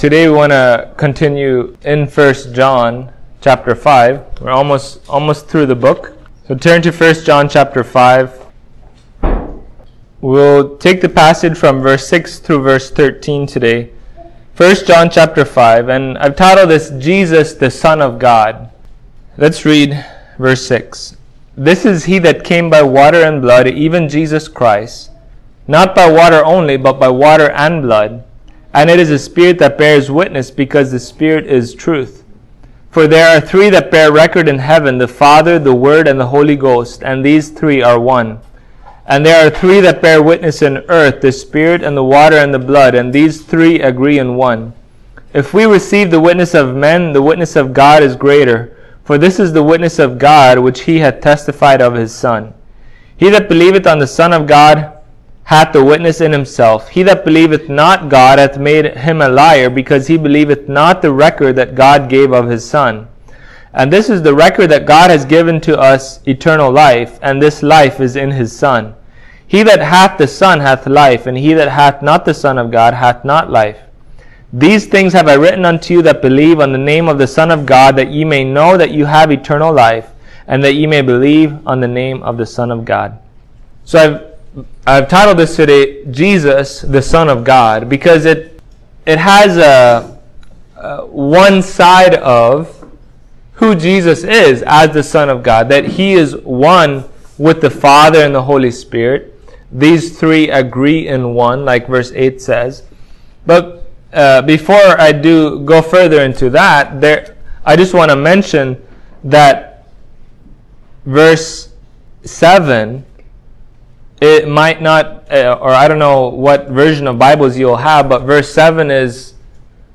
1 John 5:6-13 Service Type: Sunday Morning How does a Believer know that he knows he has eternal life?